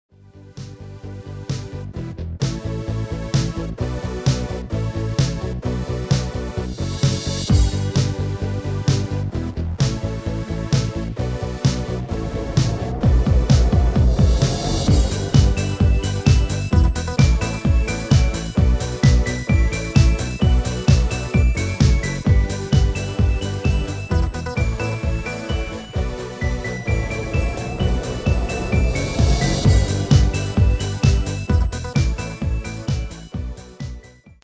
Demo/Koop midifile
Genre: Pop & Rock Internationaal
Toonsoort: G
Demo = Demo midifile